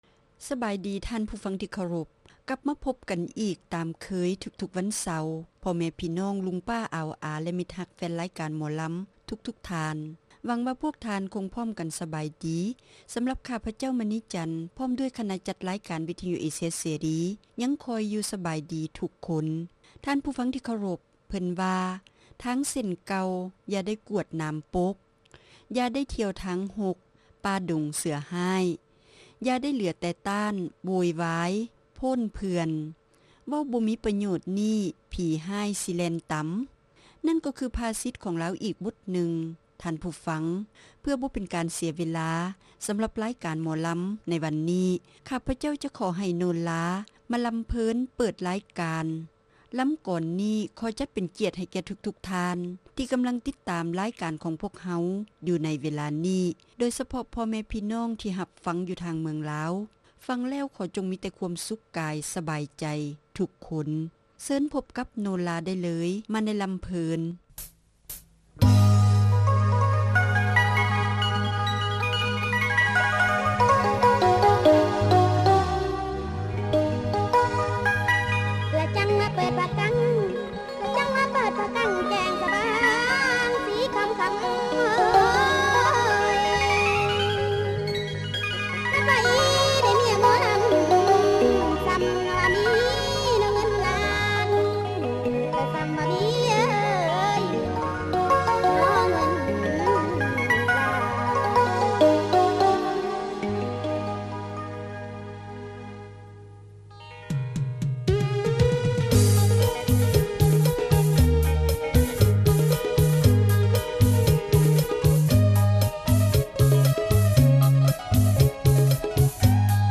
ຣາຍການໜໍລຳປະຈຳສັປະດາ